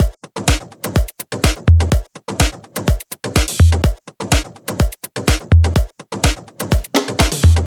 • house drum roll.wav
house_drum_roll_0pd.wav